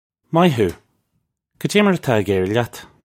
Moy hoo. Kadjay mar atah ig eer-ree lyat? (U)
This is an approximate phonetic pronunciation of the phrase.